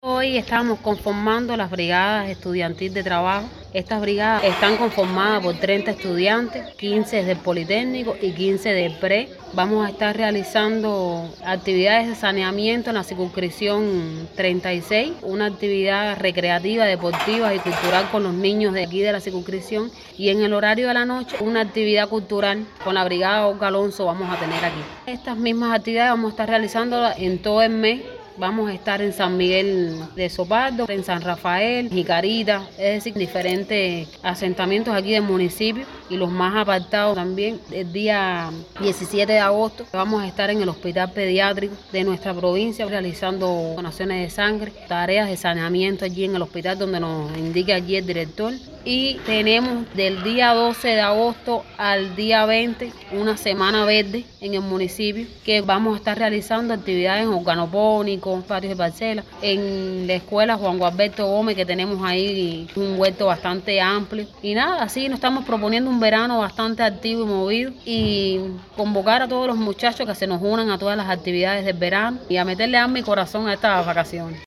PEDRO BETANCOURT.- La escuela politécnica Leonor Pérez Cabrera, de este municipio, acogió la ceremonia de abanderamiento de las Brigadas Estudiantiles de Trabajo en el territorio.